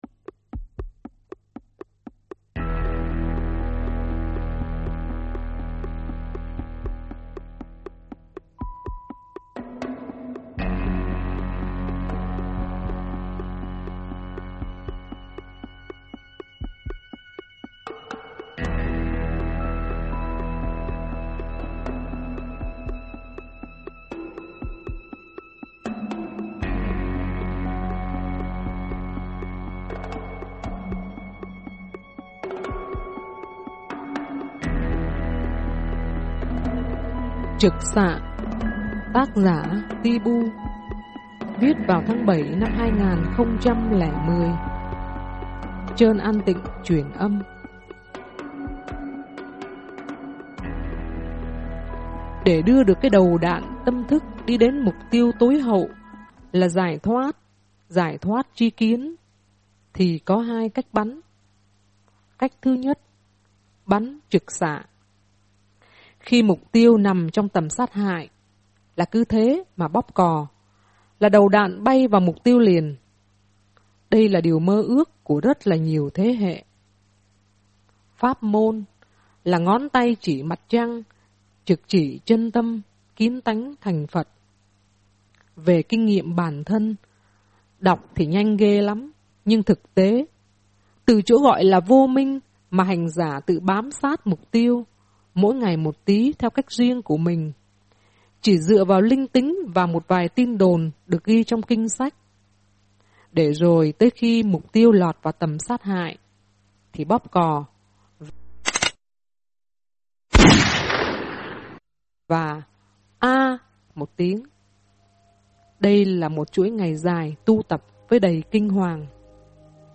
lồng vào đó 1 tiếng bóp cò